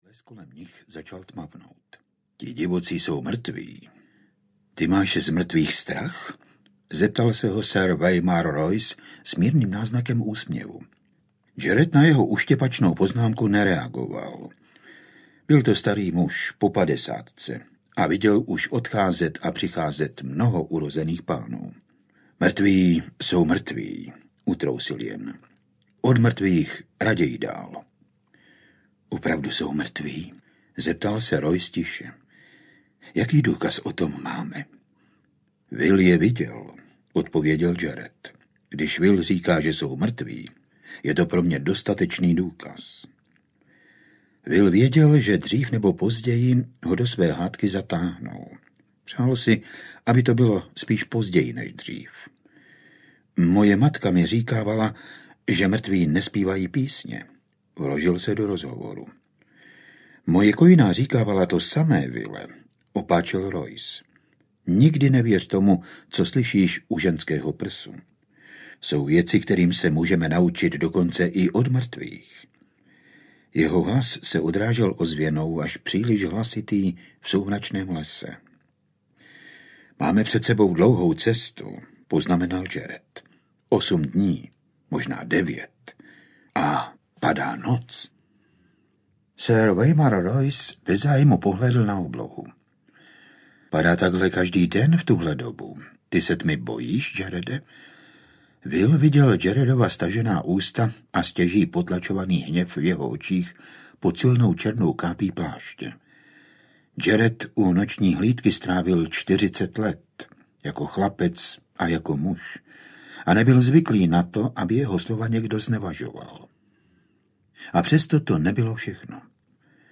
Píseň ledu a ohně audiokniha
Ukázka z knihy
• InterpretFrantišek Dočkal